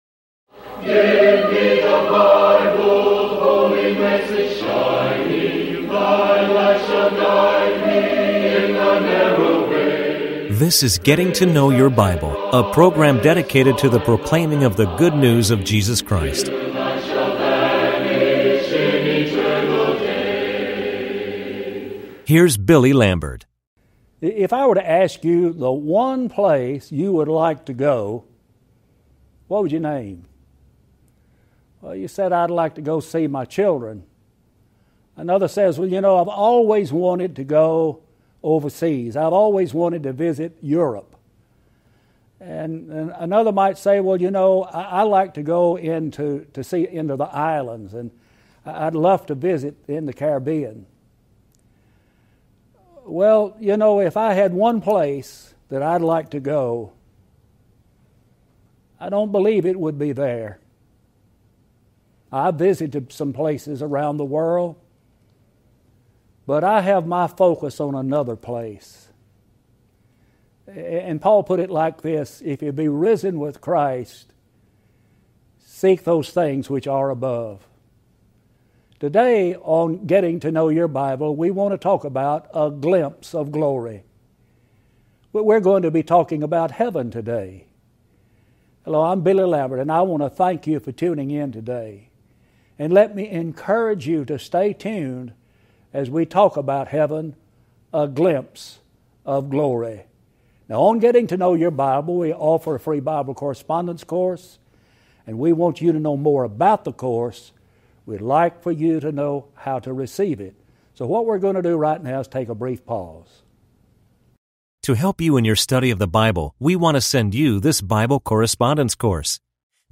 Bible Studies Show
Talk Show